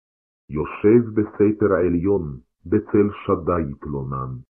Ascolta la lettura in ebraico di Salmo 91:1